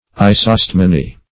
isostemony - definition of isostemony - synonyms, pronunciation, spelling from Free Dictionary Search Result for " isostemony" : The Collaborative International Dictionary of English v.0.48: Isostemony \I`so*stem"o*ny\, n. (Bot.) The quality or state of being isostemonous.